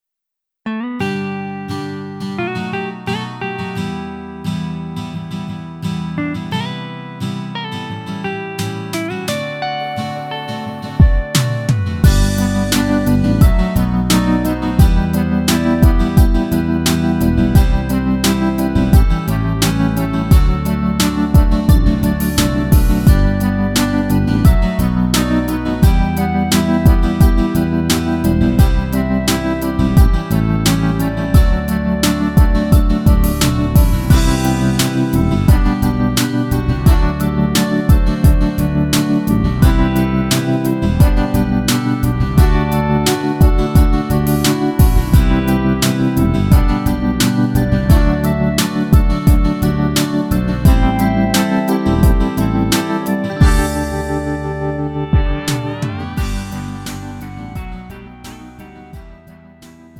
음정 원키 2:30
장르 가요 구분 Lite MR